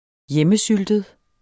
Udtale [ -ˌsyldəd ]